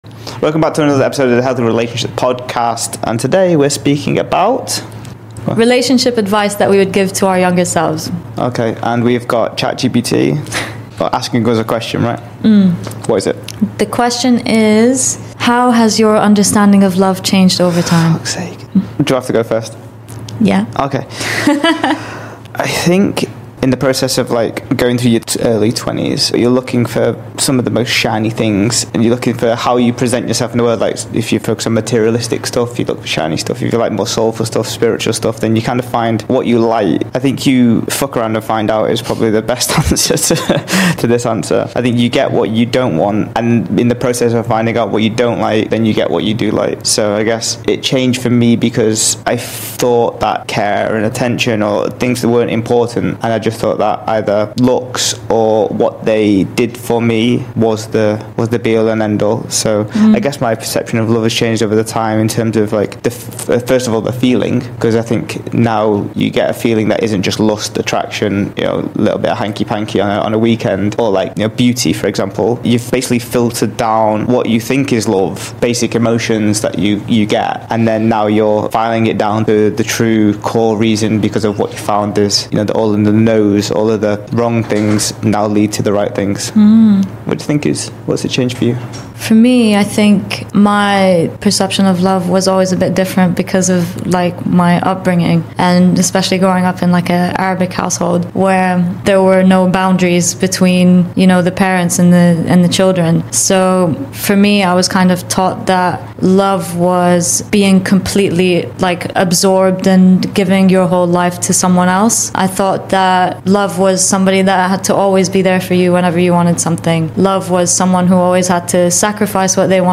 Tune in for a heartfelt and candid discussion filled with wisdom and realizations.